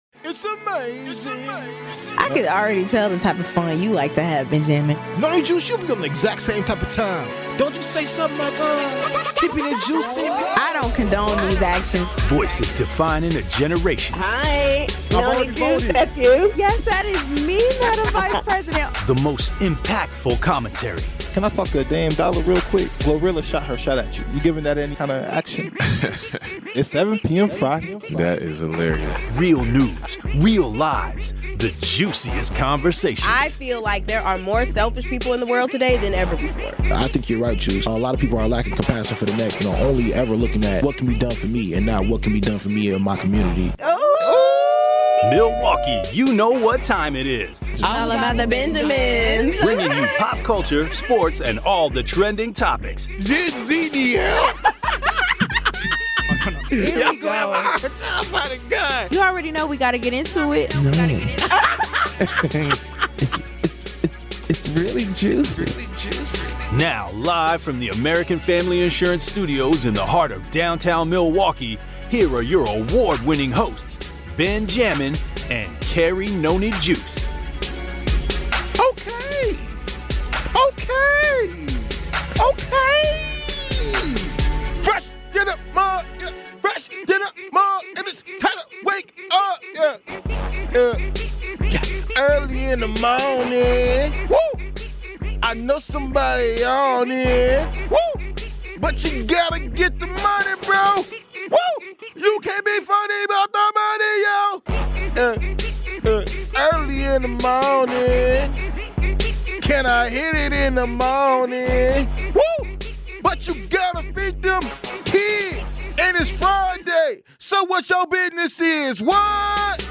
Plus, we’ll hear from local experts and residents weighing in on the impact of these plans.